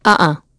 Isolet-Vox-Deny.wav